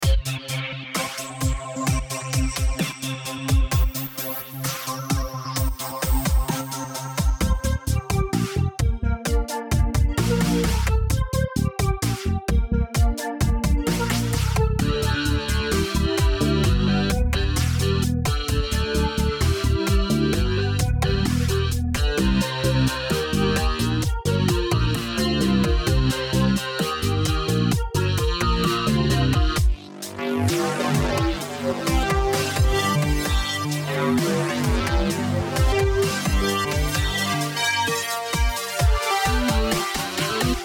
Home > Music > Electronic > Bright > Medium > Laid Back